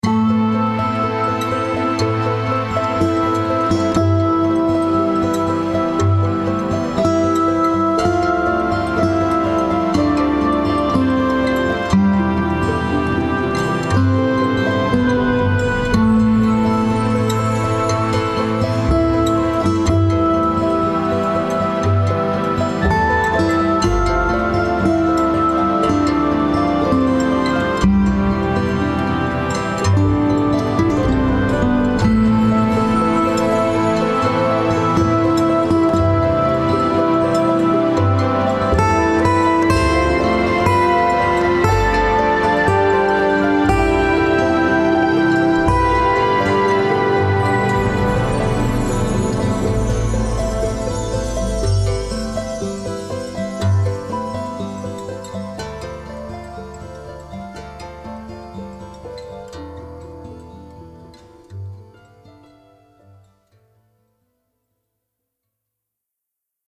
For 6 string Acoustic guitar, Hammered dulcimer with MIDI orchestration for backing using a Roland JV1080 with the optional orchestral samples card.